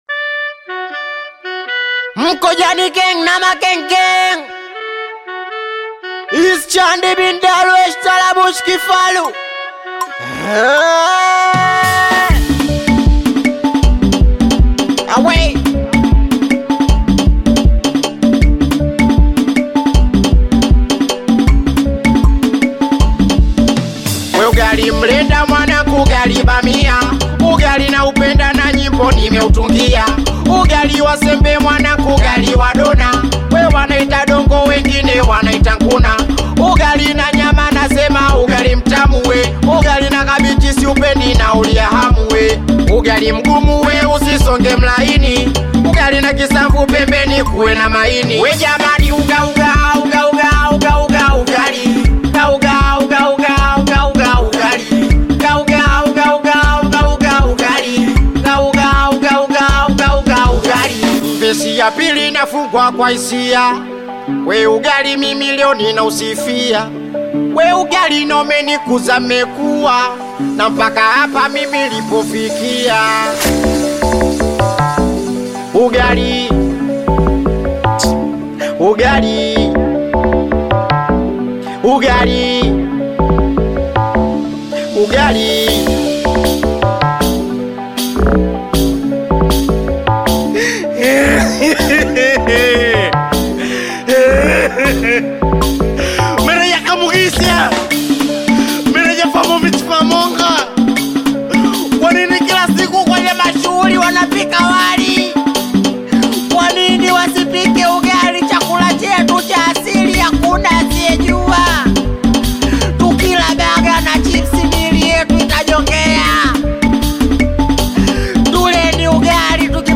Tanzanian Bongo Flava
Singeli You may also like